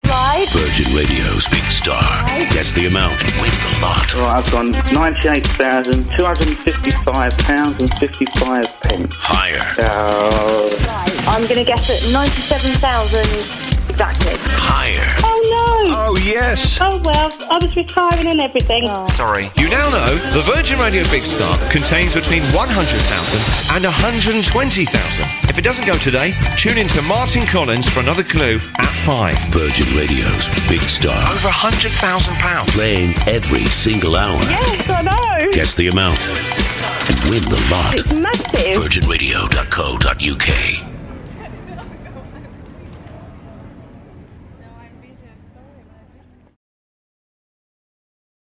Promos fill out tracks 6 through 18.